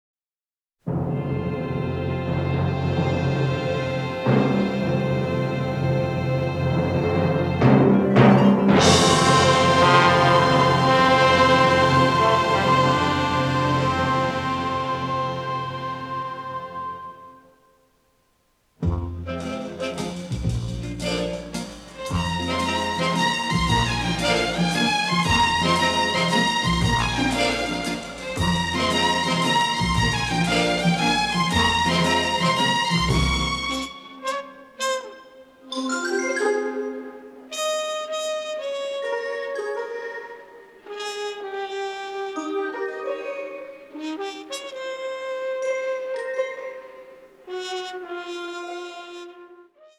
vibrant, funny, powerfully melodic
The recording took place at CTS Studios in Bayswater